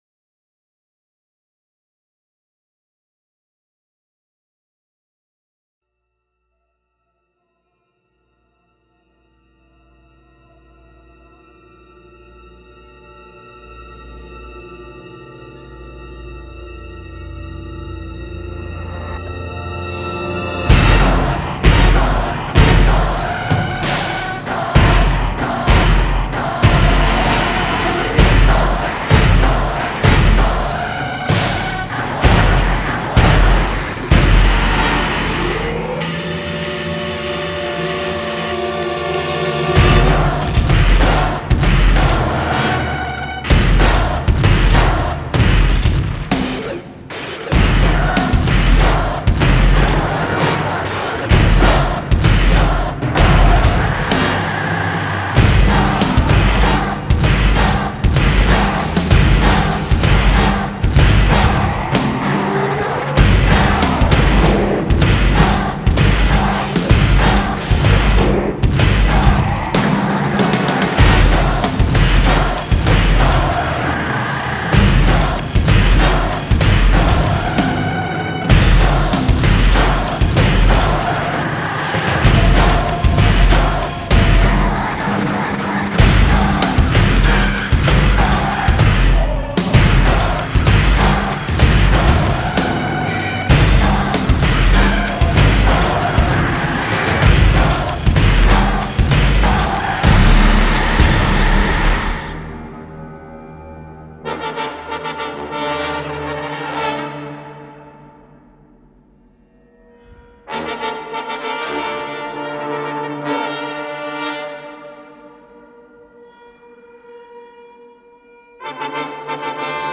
performance symphonique multimédia